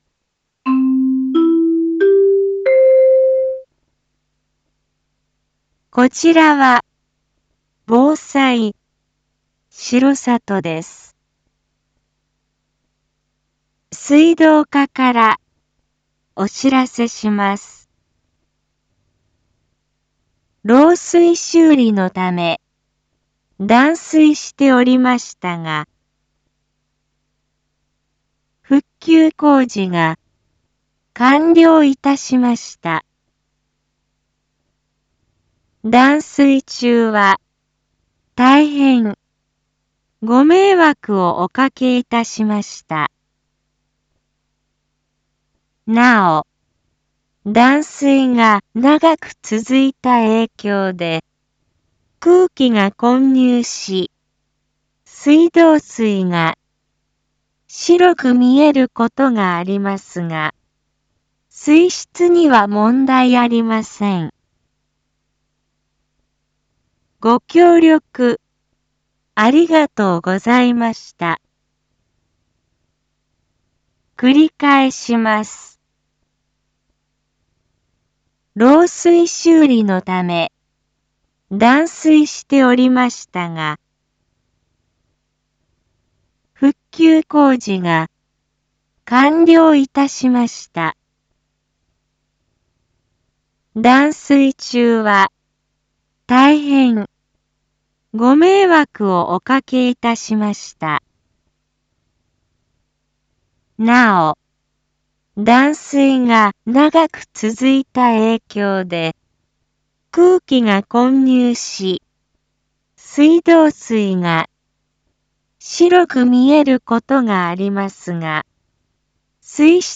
Back Home 一般放送情報 音声放送 再生 一般放送情報 登録日時：2021-12-15 10:52:32 タイトル：R3.12.15 緊急放送分（２） インフォメーション：こちらは防災しろさとです。